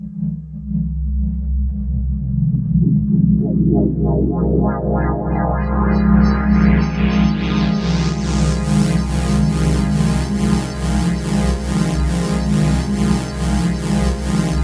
SWEEP01   -R.wav